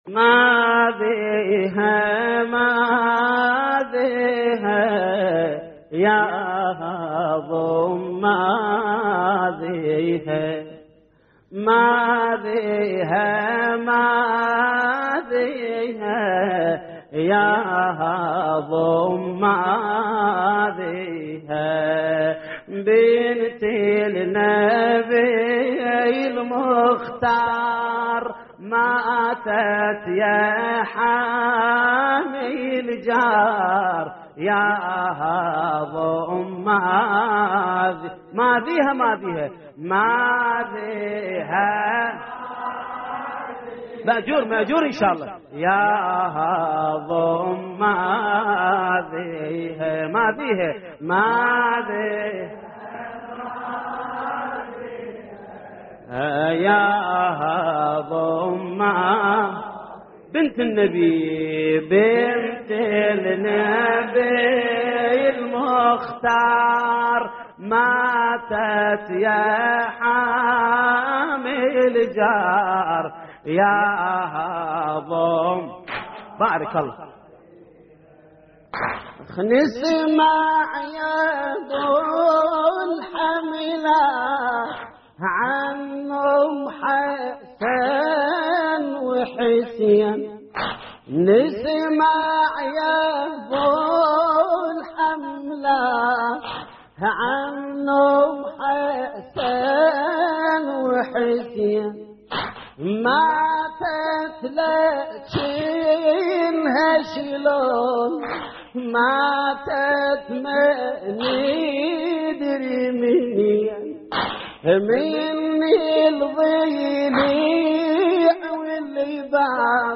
تحميل : ماذيها ماذيها يا هضم ماذيها بنت النبي المختار ماتت يا حامي الجار / الرادود جليل الكربلائي / اللطميات الحسينية / موقع يا حسين